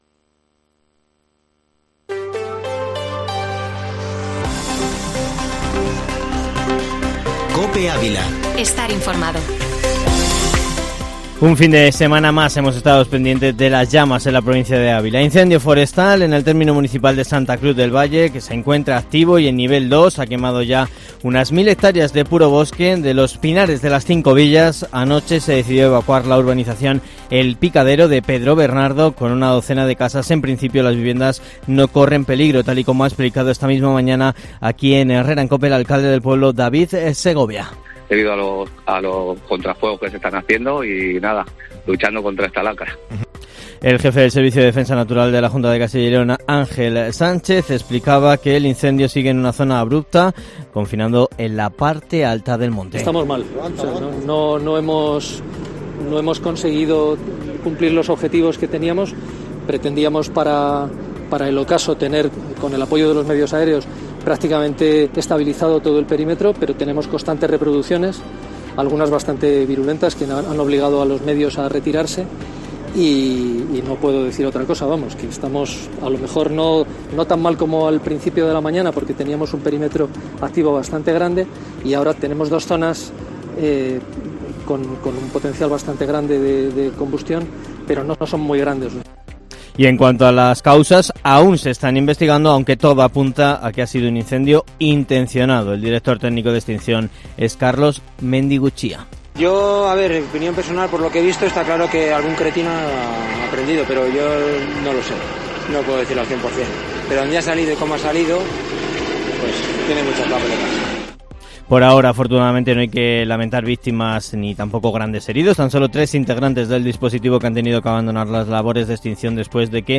Informativo Matinal Herrera en COPE Ávila -8-agosto